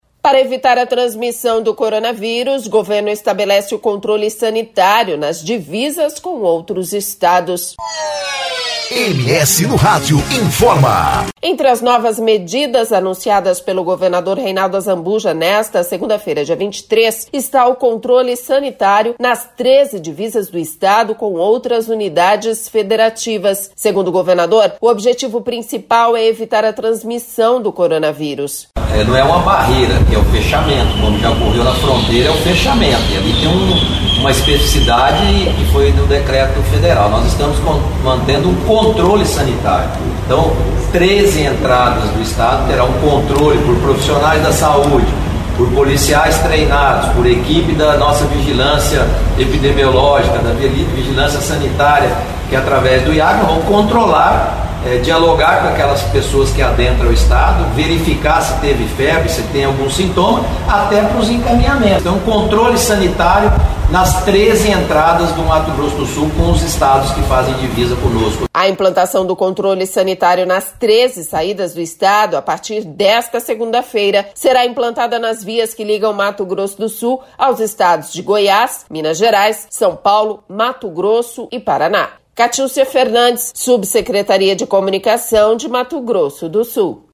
24.03-BOLETIM-CONTROLE-SANITÁRIO-PORTAL.mp3